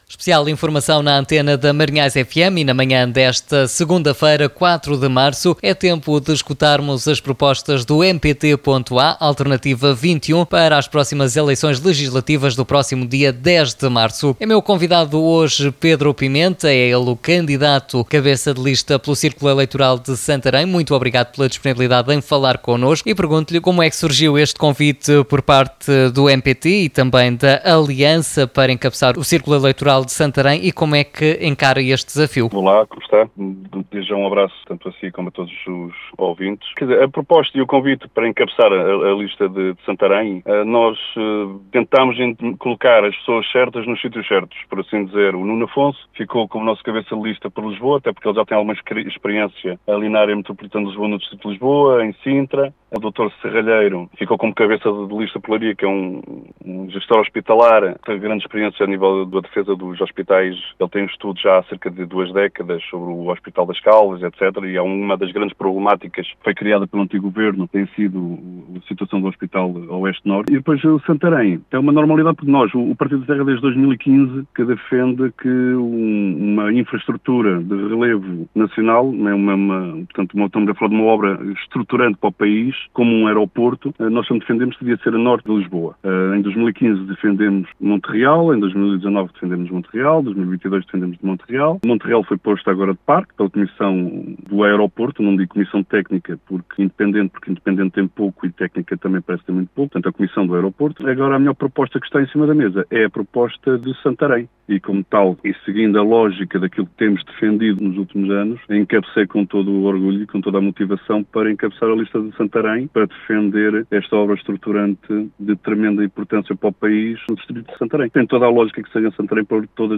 Em entrevista à Rádio Marinhais, o candidato aproveitou para reforçar a ideia, que o MPT já tem vindo a defender há alguns anos e noutros atos eleitorais, de localizar o Novo Aeroporto do país em Santarém e não em Alcochete, como foi apontado, em dezembro, pela Comissão Técnica Independente (CTI).